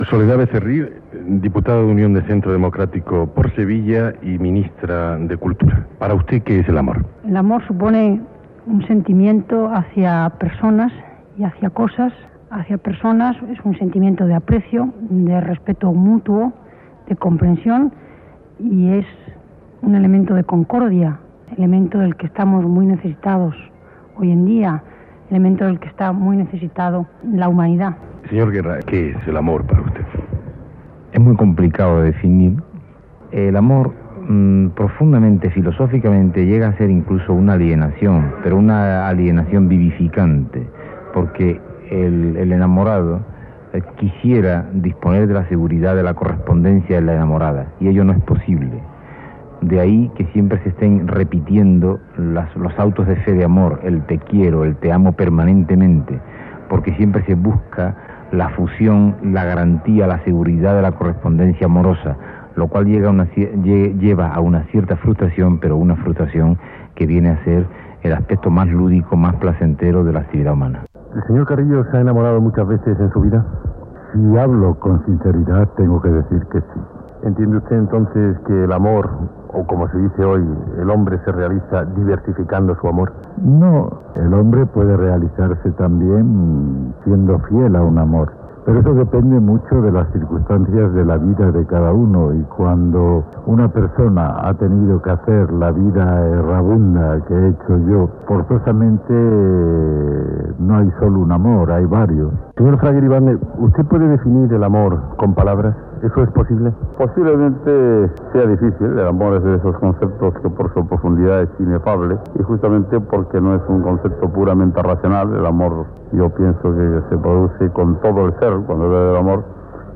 Pregunta a la ministra Soledad Becerril, i els diputat Alfonso Guerra, Santiago Carrillo i Manuel Fraga Iribarne sobre la seva idea d'amor
Entreteniment